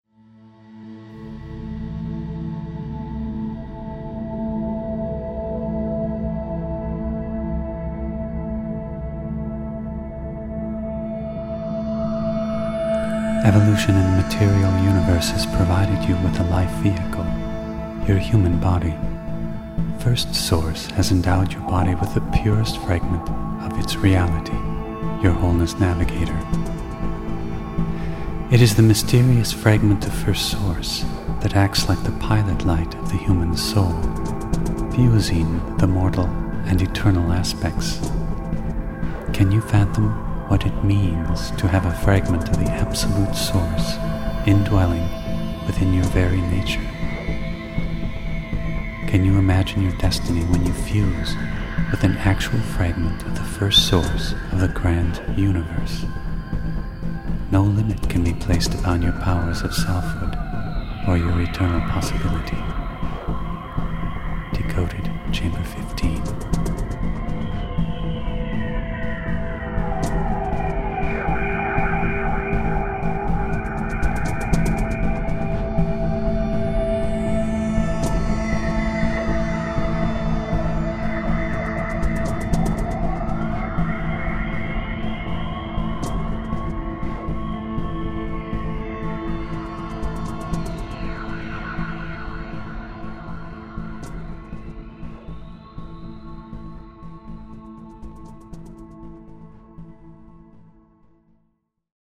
Filosofia Inediti – Audiolettura